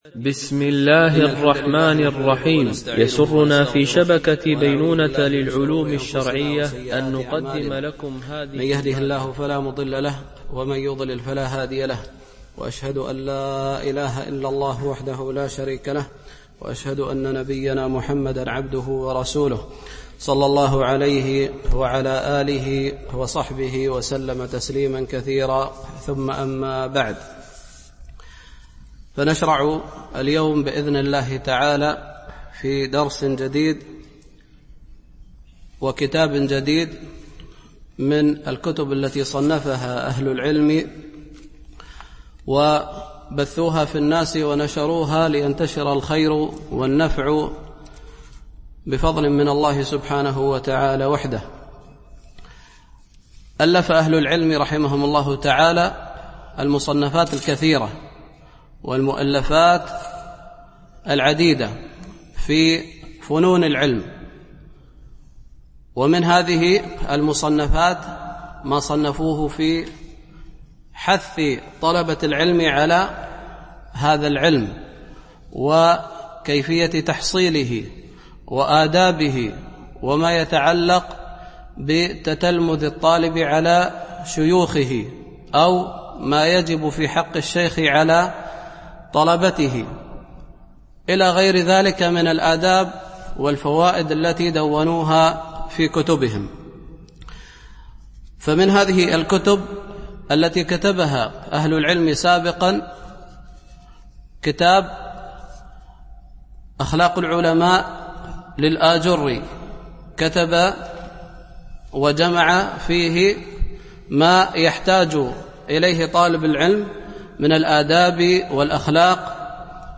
شرح كتاب العلم لأبي خيثمة ـ الدرس 1 (الأثر 1 - 2)